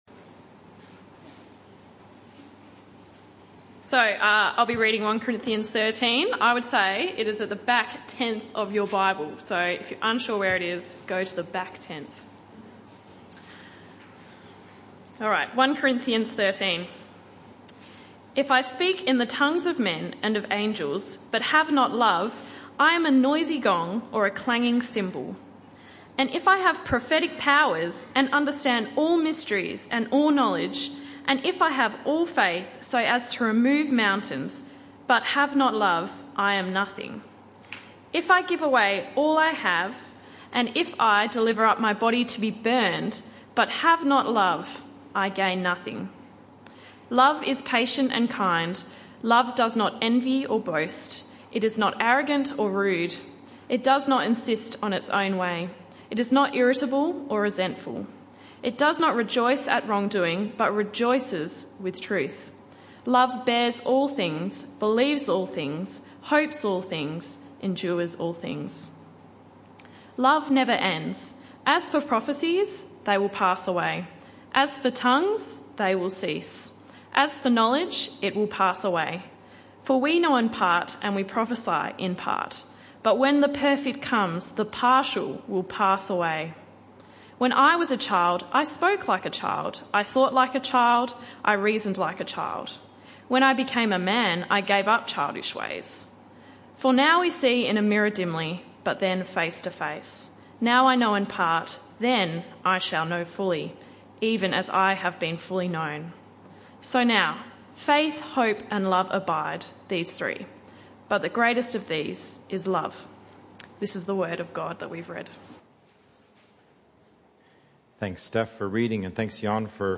This talk was the first in the AM+PM Service series entitled A More Excellent Way (Talk 1 of 2).
Service Type: Evening Service